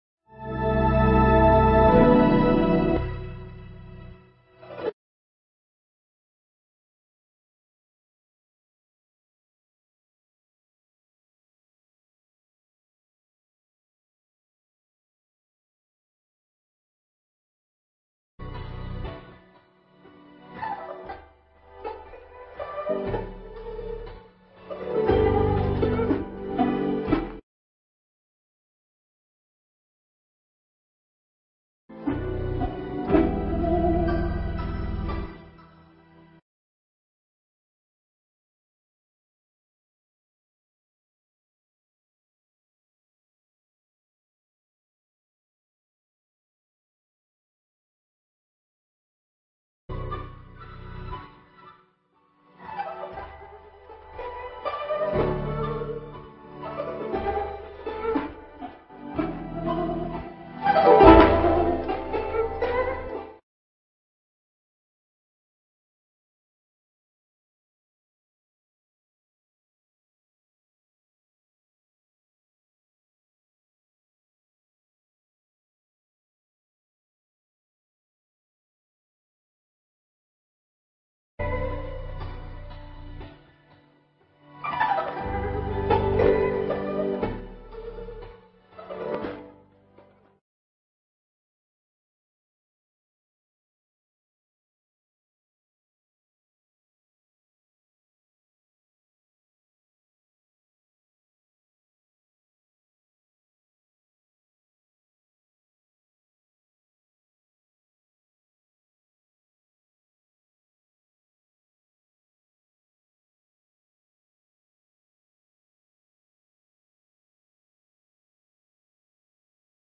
Nghe Mp3 thuyết pháp Ý Nghĩa Và Mục Đích Quy Y Tam Bảo